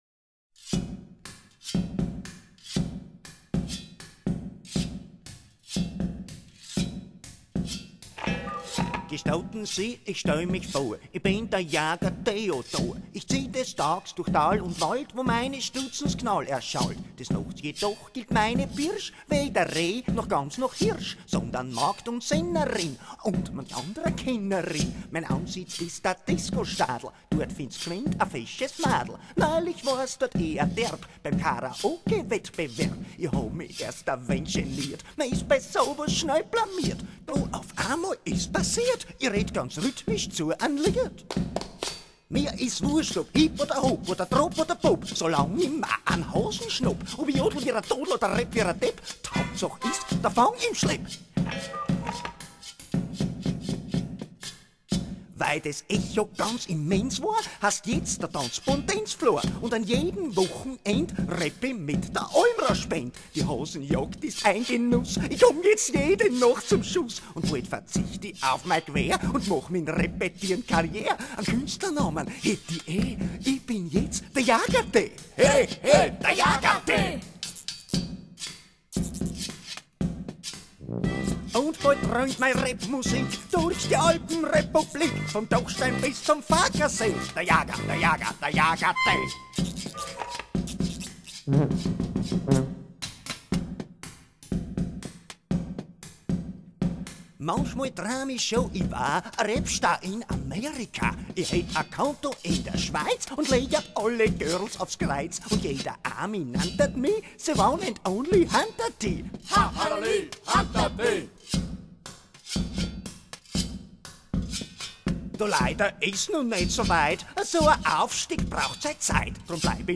Wir hören die dunkelsten und buntesten Früchte langjährigen Liedschaffens, kulinarisch serviert mit Gitarre, Dobro, Mund- und Knöpferlharmonika, Maultrommel, Räptil und Quietschratte - im urigen Sittl-Ambiente - Kleinkunst, angesiedelt zwischen Kabarett und Liedermacherei, an der Grenze von Musik und Literatur.